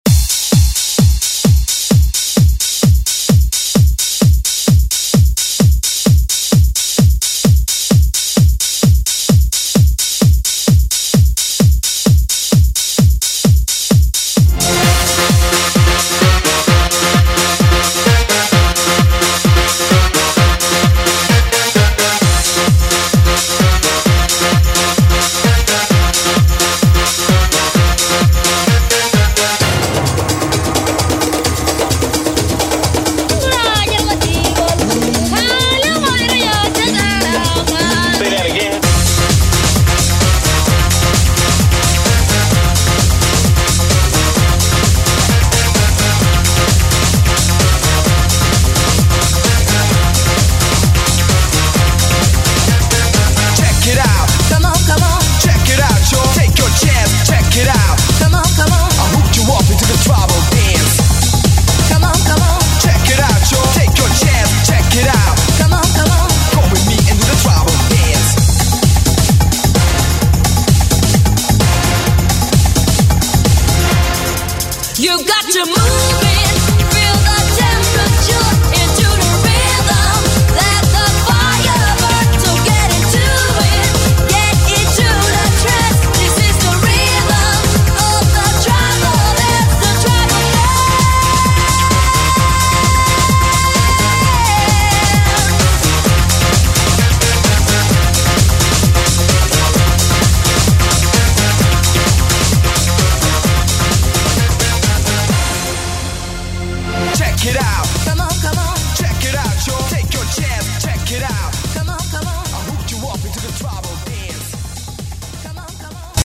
Electronic Latin Music Medley Mix Extended Intro Outro
120 bpm
Genre: 90's